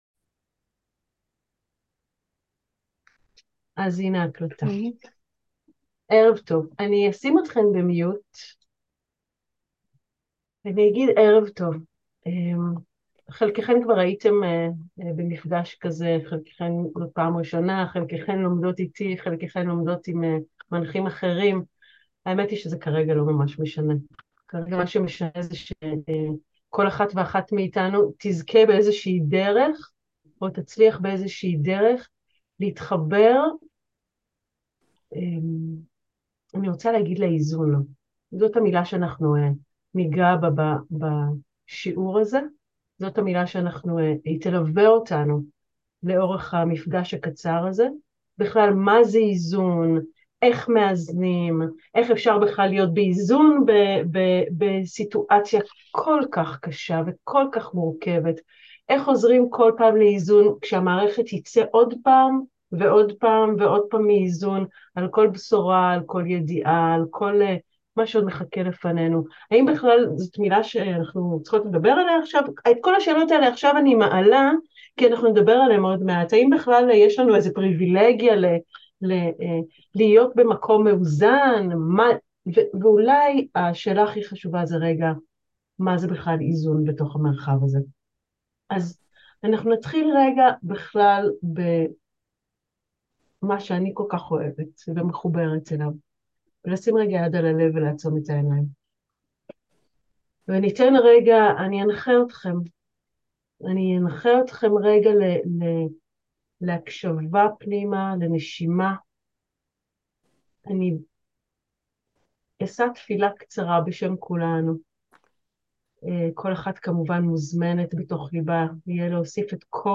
הקלטה מתוך שיעורים פתוחים